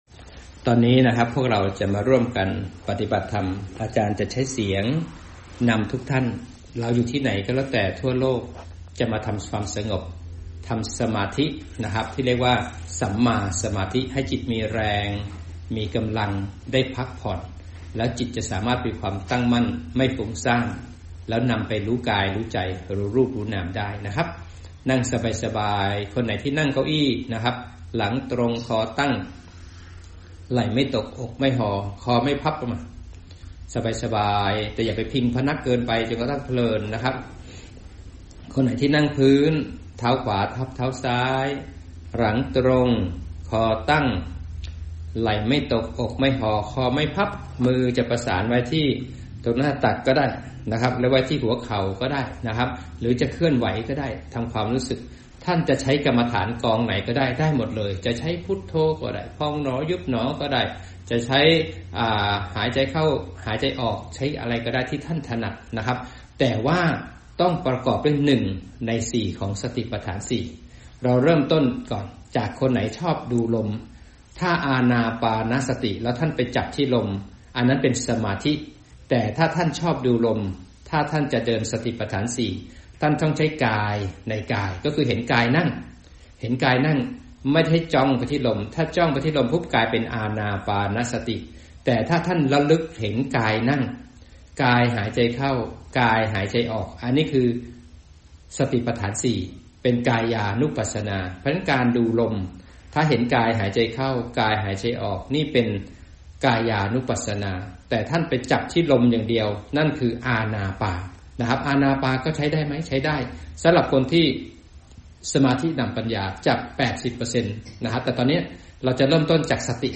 อจ นำฝึกสัมมาสมาธิ สมาธินำปัญญา